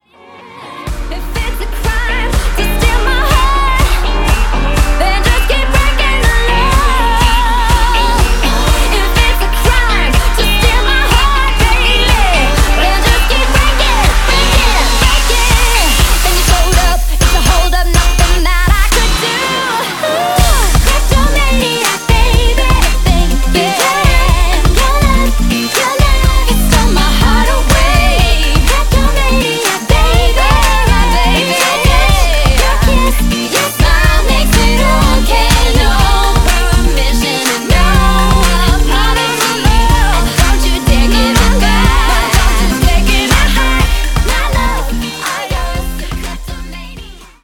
EDM
Dance